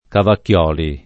Cavacchioli [ kavakk L0 li ] cogn.